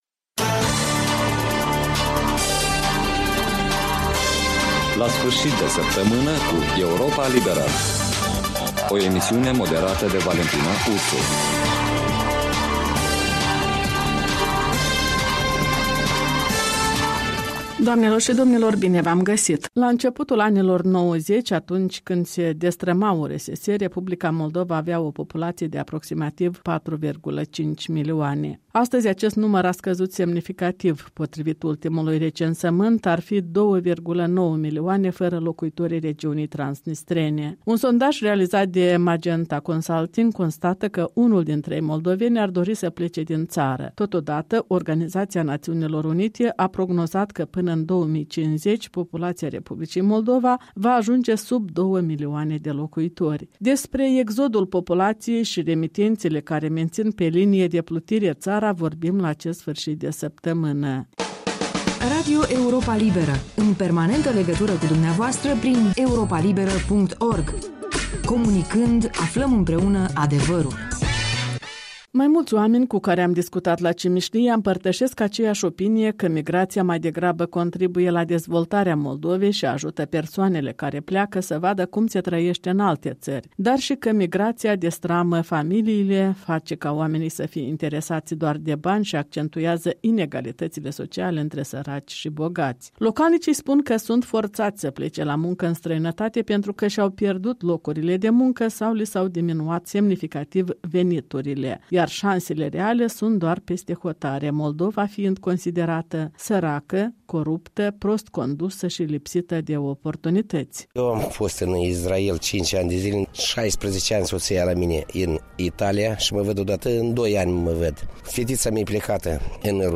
în dialog cu locuitori din Cimișlia